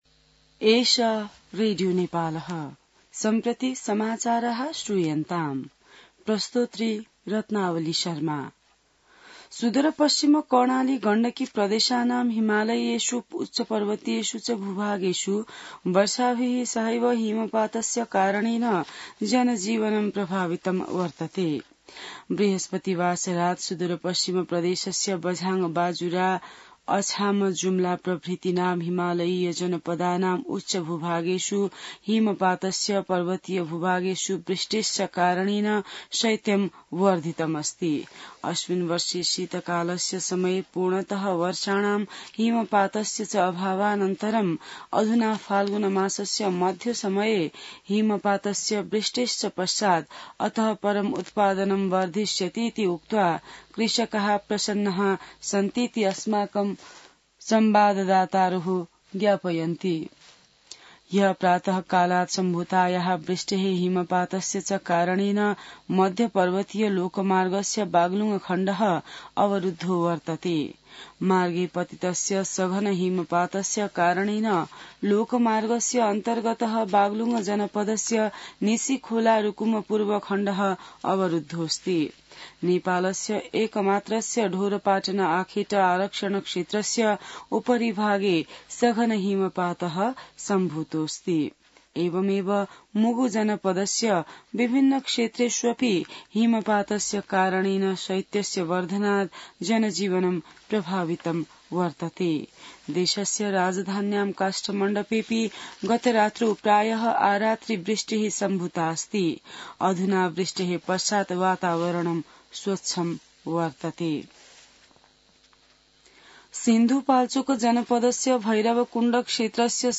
An online outlet of Nepal's national radio broadcaster
संस्कृत समाचार : १८ फागुन , २०८१